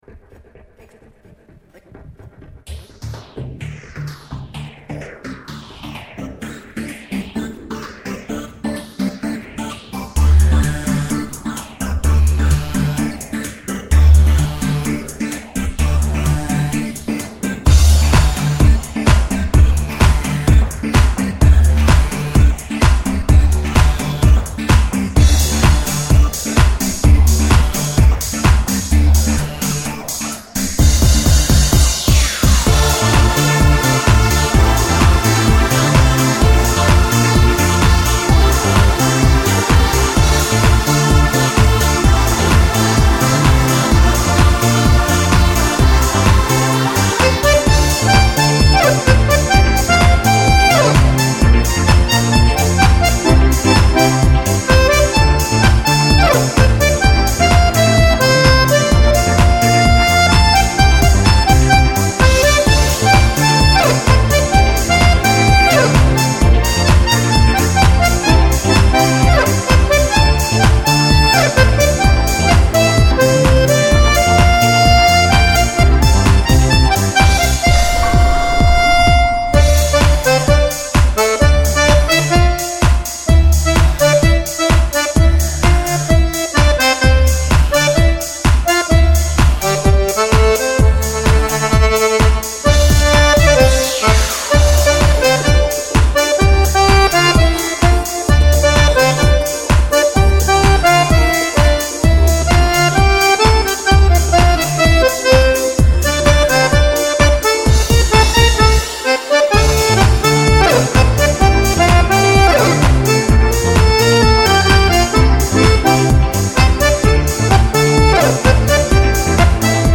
悠悠的手风琴重新演绎的经典情歌
轻松优美的旋律